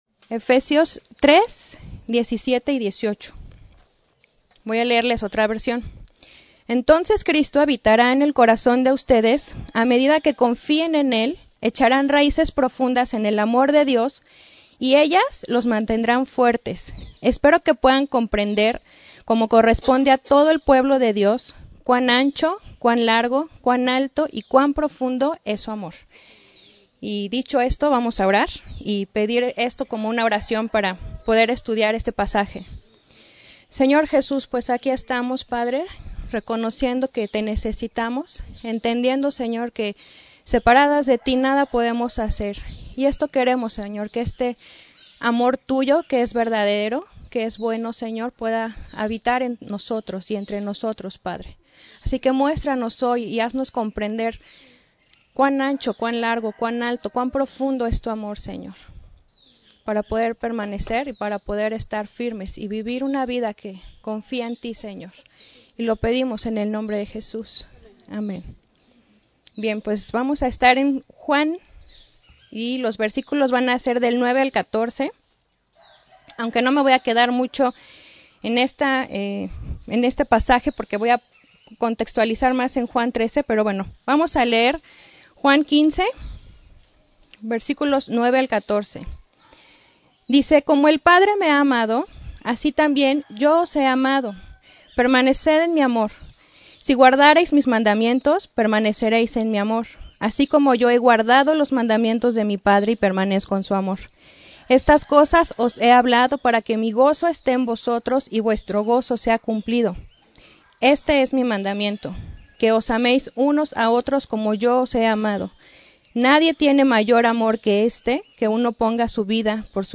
Retiro Staff 2018